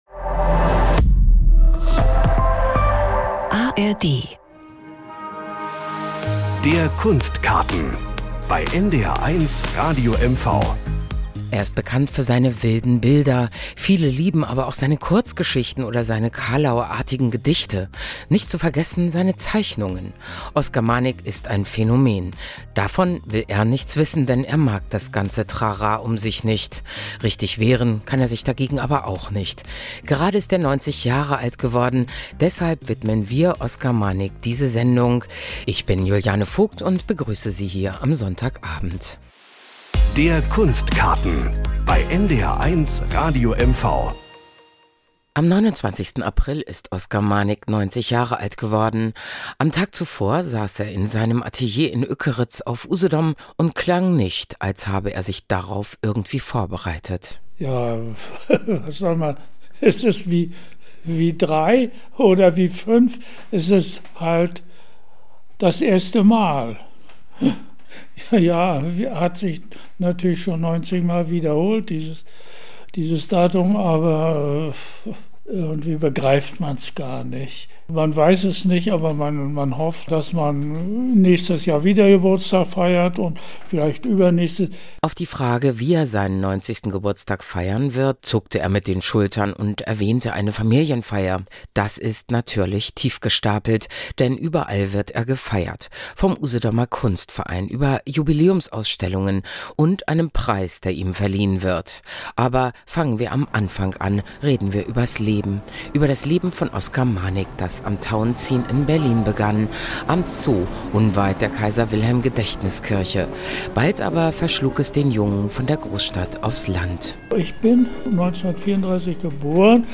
Nachrichten aus Mecklenburg-Vorpommern - 13.06.2024